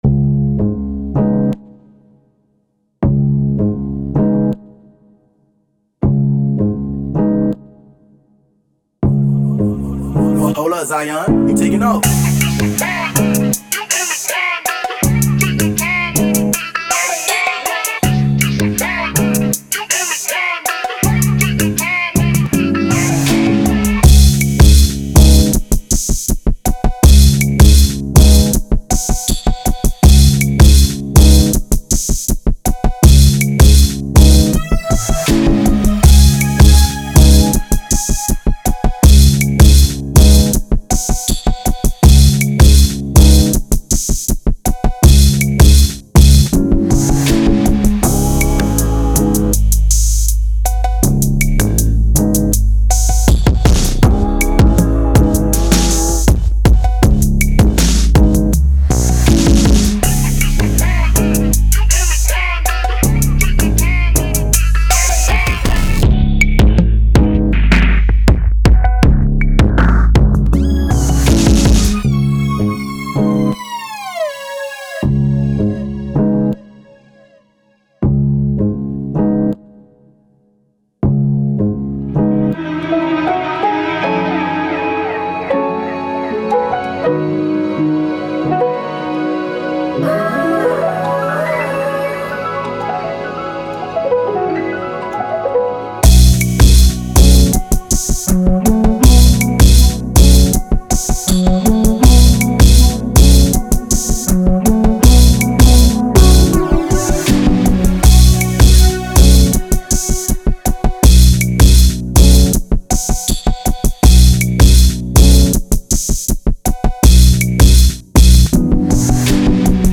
80 F# Minor